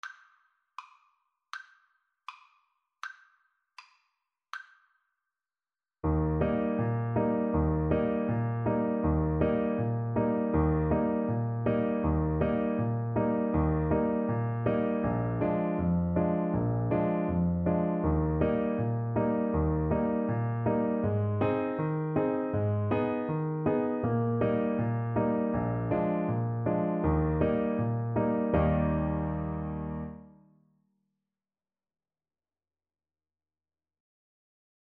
4/4 (View more 4/4 Music)